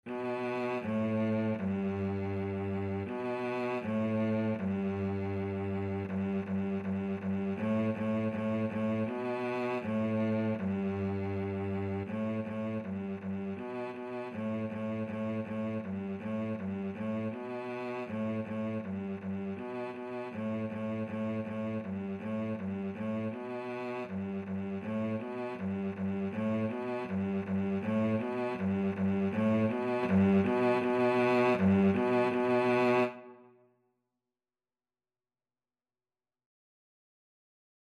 2/4 (View more 2/4 Music)
G3-B3
Beginners Level: Recommended for Beginners
Cello  (View more Beginners Cello Music)
Classical (View more Classical Cello Music)